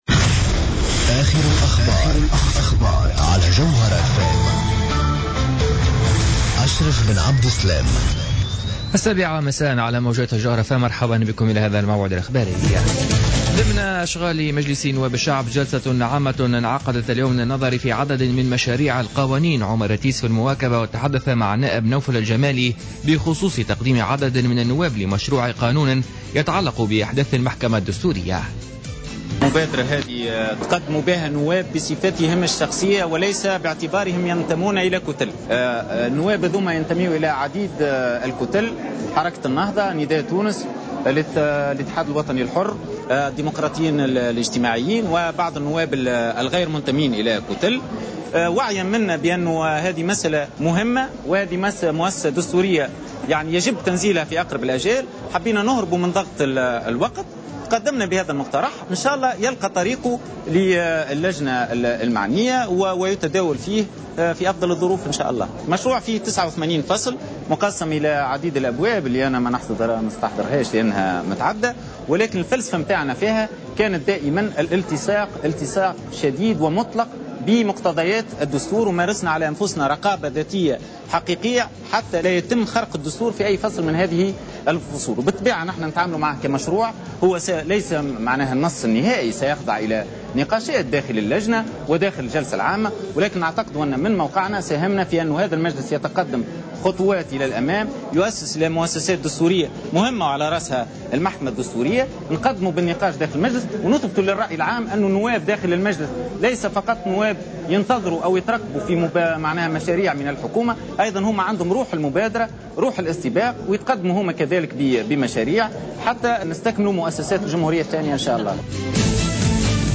نشرة أخبار السابعة مساء ليوم الثلاثاء 02 جوان 2015